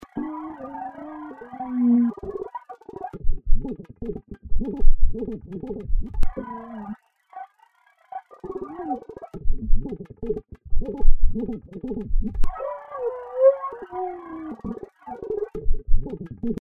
Wie abgedreht die Sache ist, hört man noch deutlicher, wenn man die wimmernde Bassdrum auf Solo schaltet: